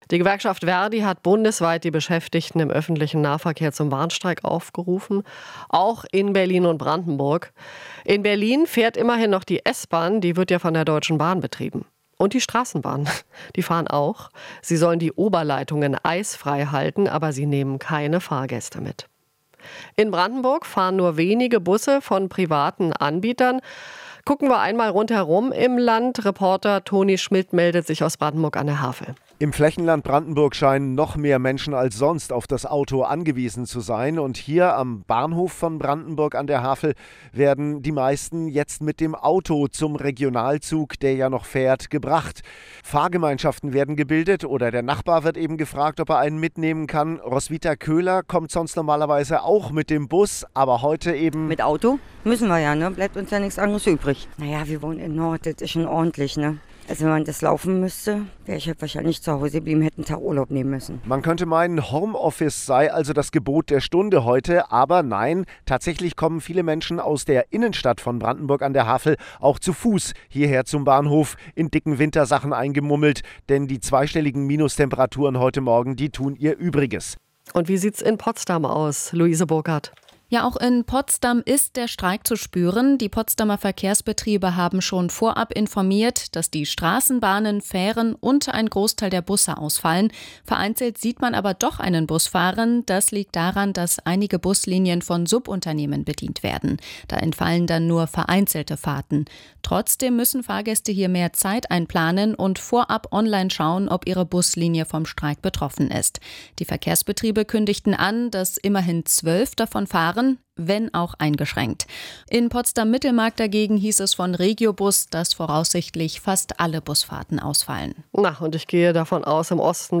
Alles was wichtig ist in der Hauptstadtregion - in Interviews, Berichten und Reportagen.
Unsere Reporter aus den